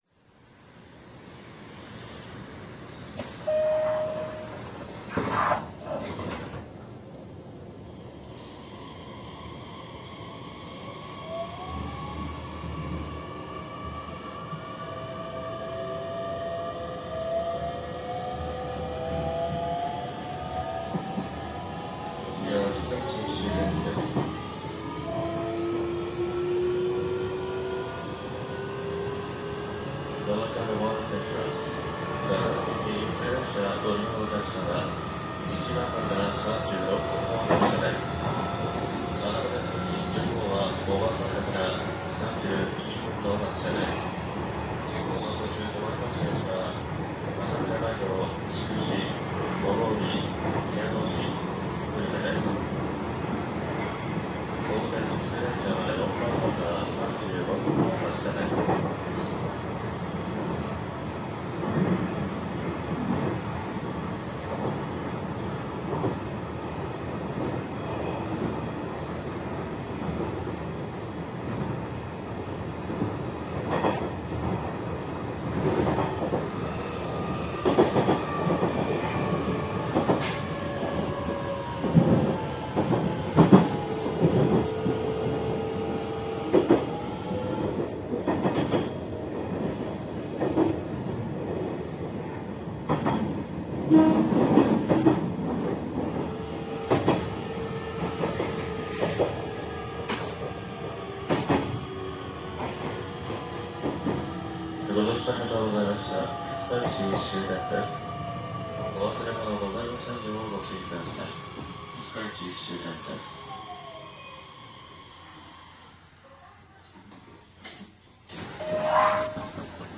走行音[nst7000a.ra/238KB]
制御方式：VVVFインバータ制御(東芝2レベルIGBT、1C3M制御、ただし1C4M制御にも対応)
主電動機：170kW(SEA-385型、歯車比101:16＝6.31)
※西鉄の最新型車両。東芝2レベルIGBTインバータ装備です。